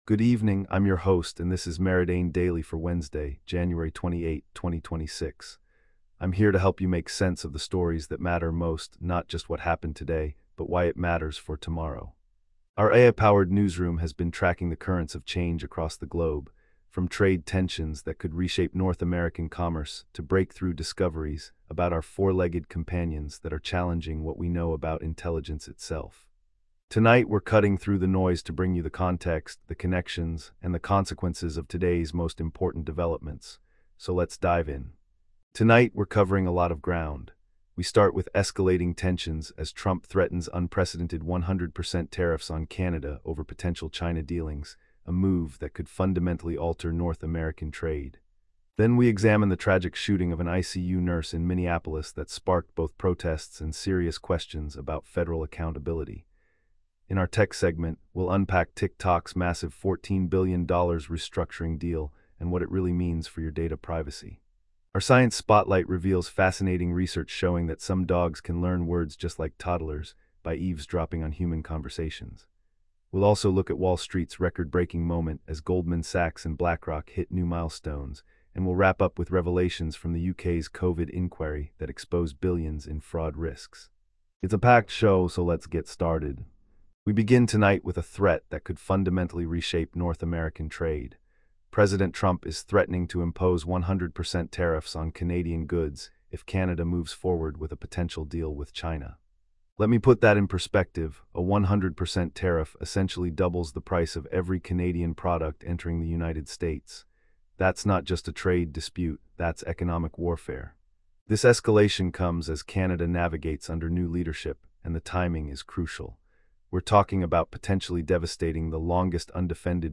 Your nightly AI-powered news briefing for Jan 28, 2026